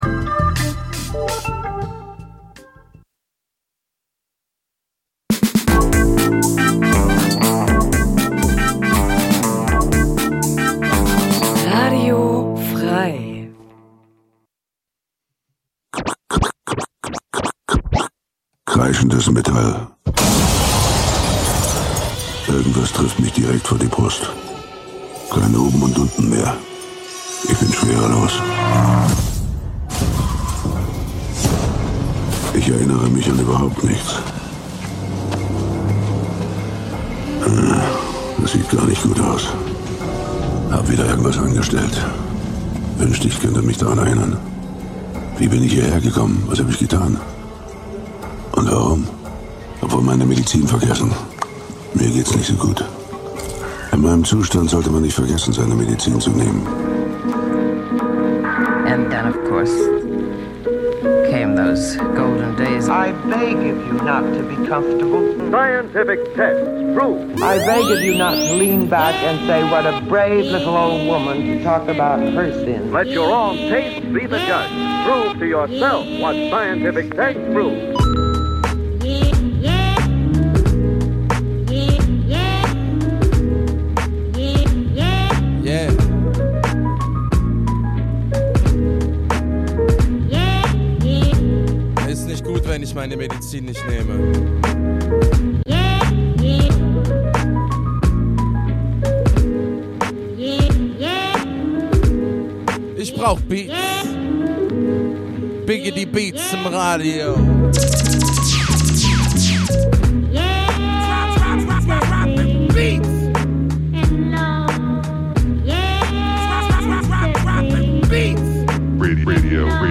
Wenn der Groove Deine Seele streichelt, die Drums ungezogen ins Ohr fl�stern, w�hrend Loops und Schleifen in entspannten Kreisen schweifen und dabei mehr gelacht als gedacht wird, sind Deine Ohren bei Radio Bounce - Gurgelnd knusprige Wellen aus der Hammerschmiede f�r leidenschafltiche Sch�ngeister, pudelnackt!